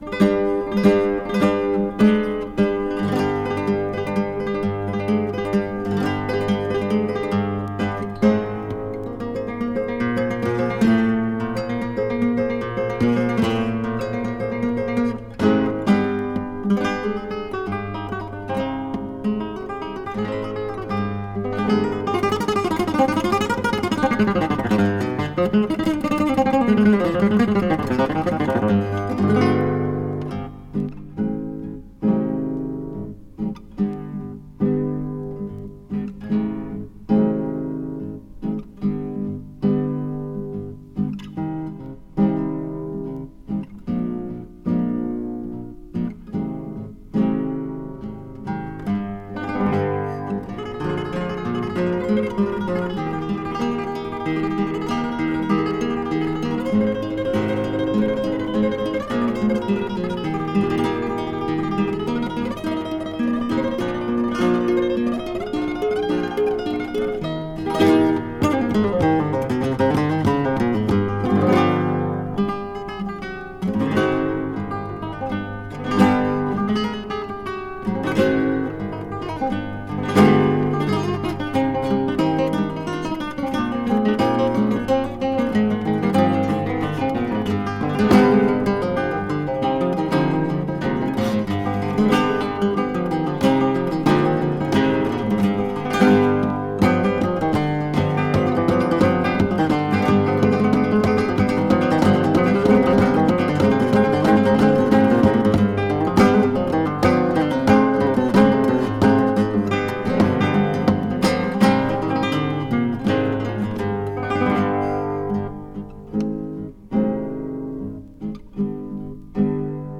fandango